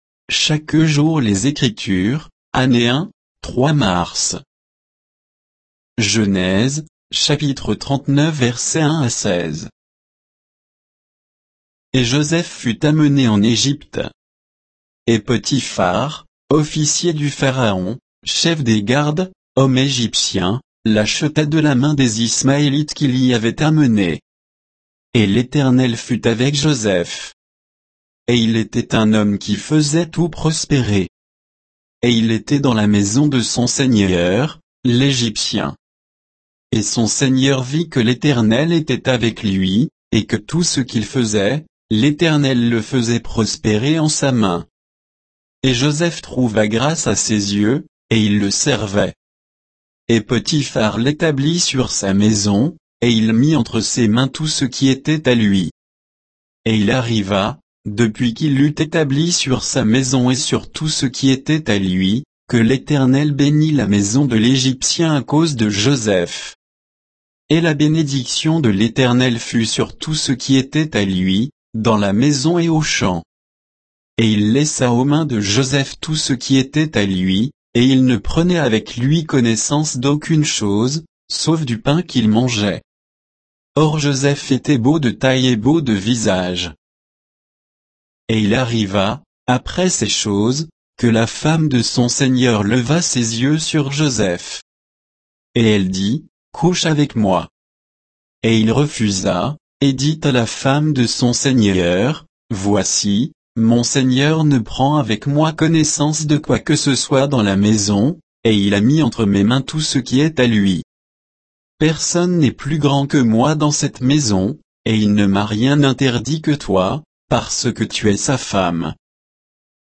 Méditation quoditienne de Chaque jour les Écritures sur Genèse 39